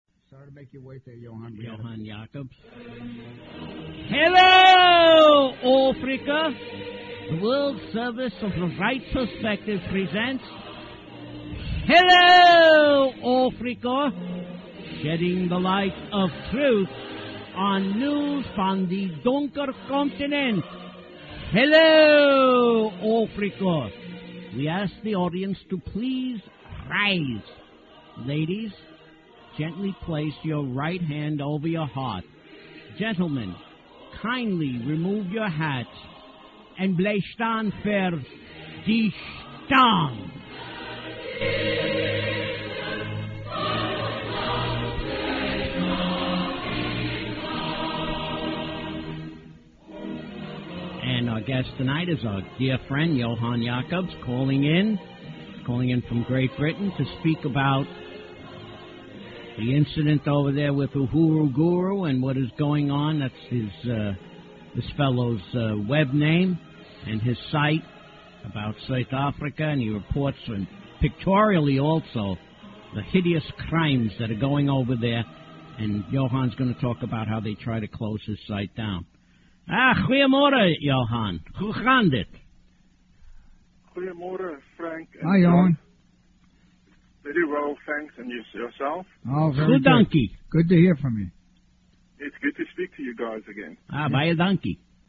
Tags: South Africa Propaganda Apartheid The Right Perspective Talk Radio